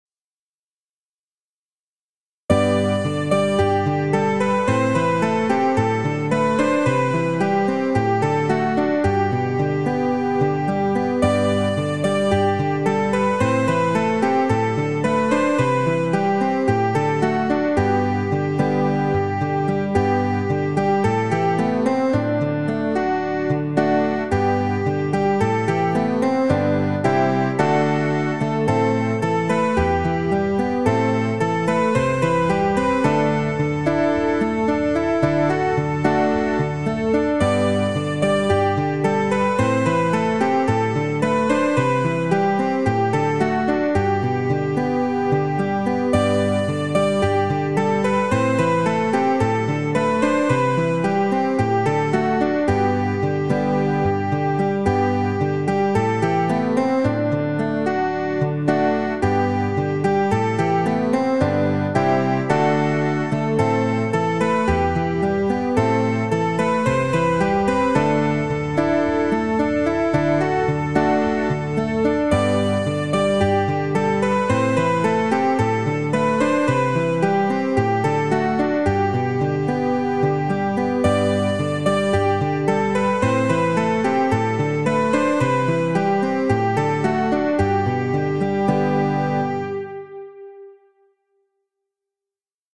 Psalm 30 (V2) Praise the Lord. A rousing praise to the Lord for His care and mercy.